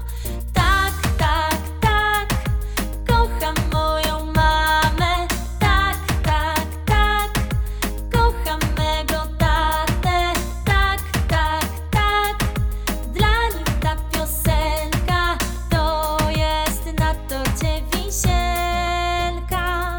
utwór w wersji wokalnej